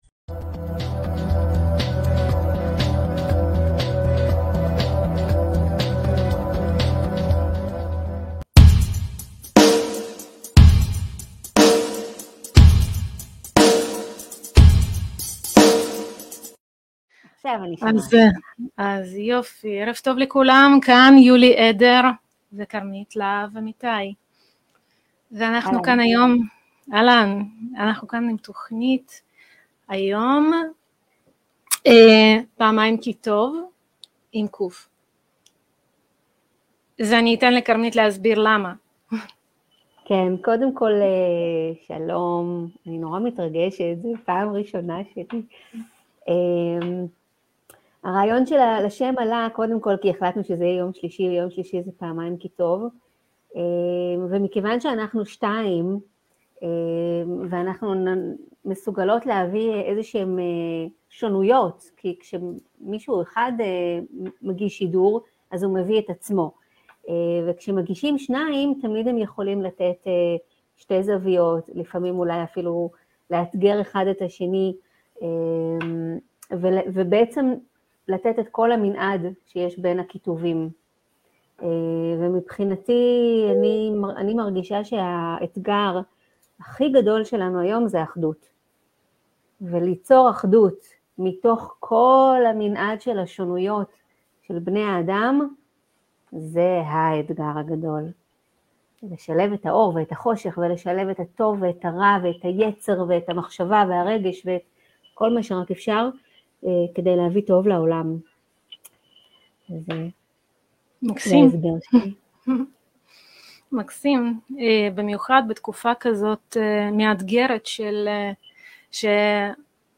שלישי פעמיים קיטוב 8-3-22 - שיחה